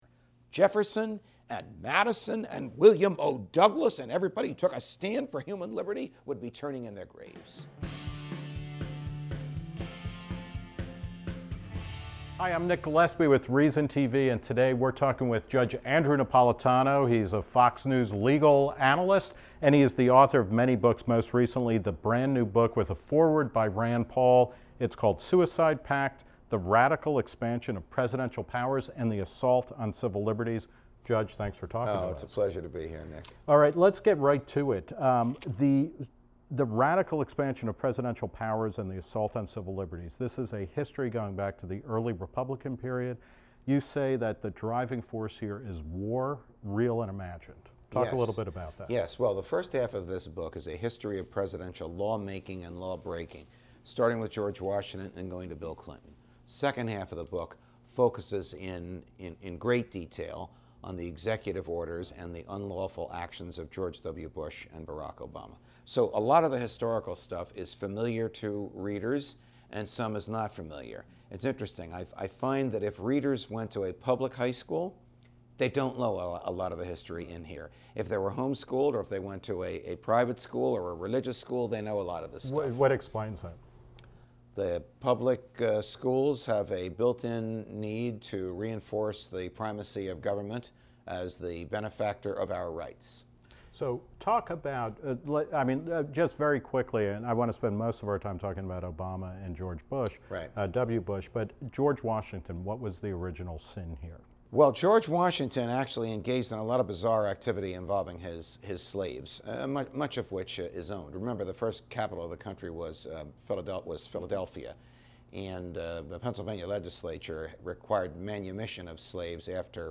The Reason Interview
The syndicated columnist, Fox News senior judicial analyst , and outspoken libertarian sat down with Reason TV's Nick Gillespie to discuss his new book, Suicide Pact:The Radical Expansion of Presidential Powers and the Assault on Civil Liberties . Napolitano discusses how presidents from George Washington to Abraham Lincoln to Woodrow Wilson to George W. Bush to Barack Obama have used war and domestics threats to massively increase the reach of the state.